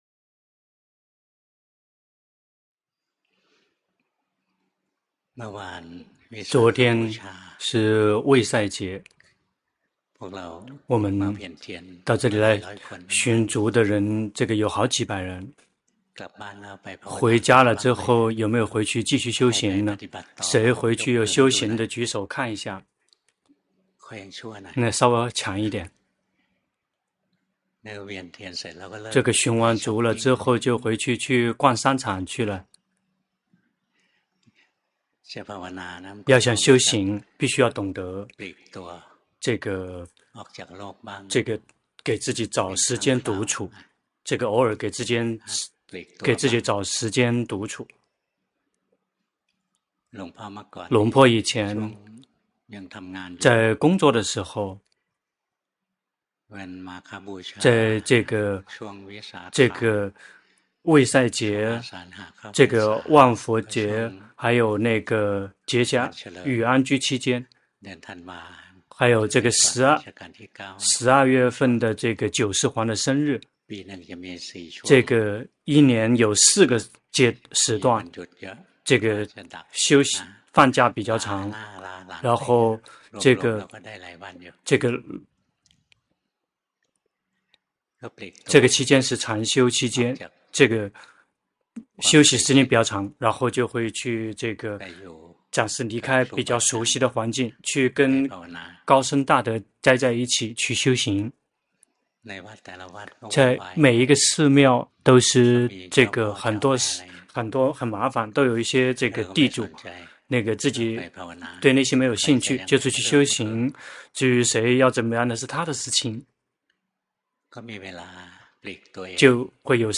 法談摘錄